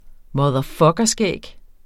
Udtale [ mʌðʌˈfʌgʌˌsgεˀg ]